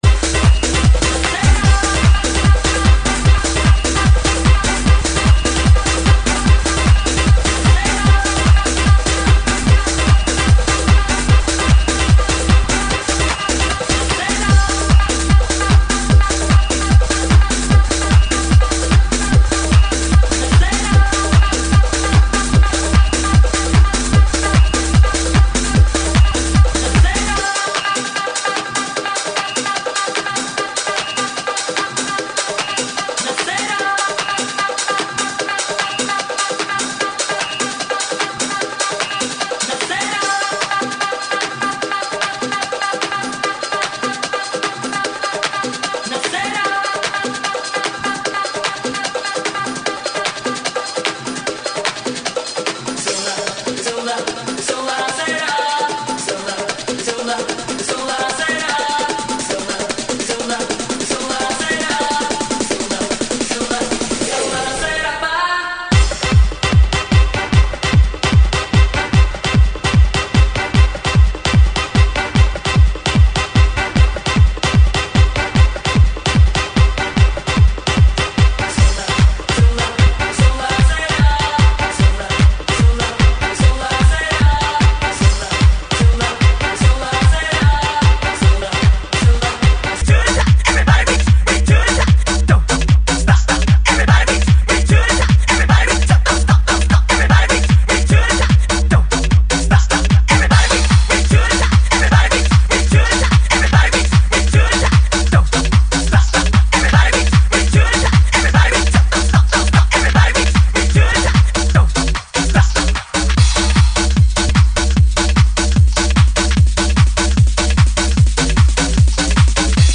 GENERO: ELECTRONICA – TECHNO